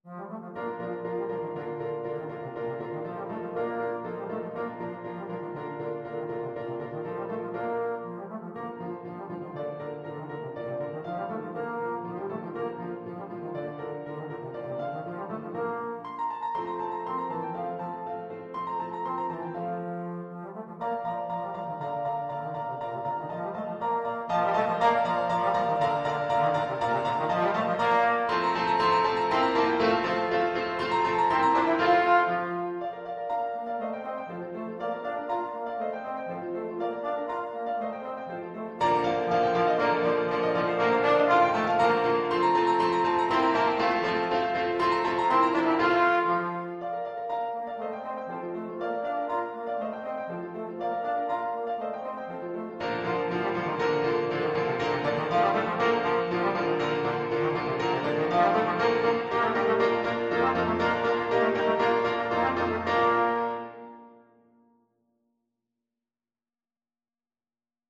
Trombone
2/4 (View more 2/4 Music)
Bb major (Sounding Pitch) (View more Bb major Music for Trombone )
Classical (View more Classical Trombone Music)